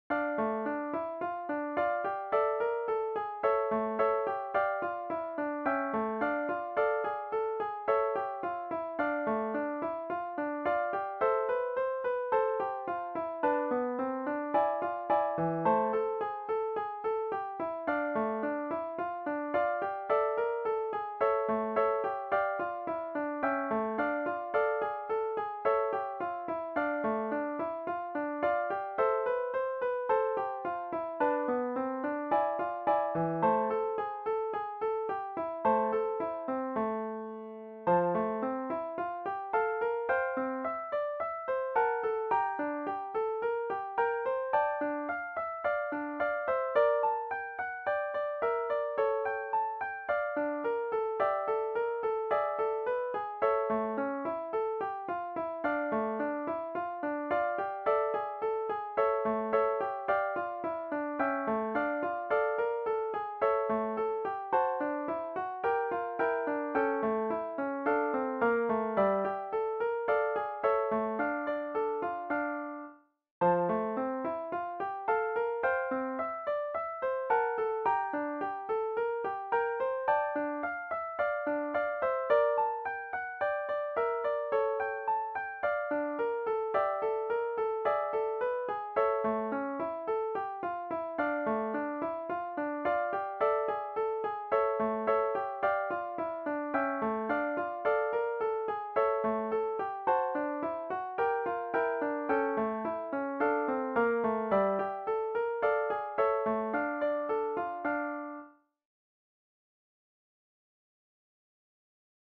arrangement for 3 guitars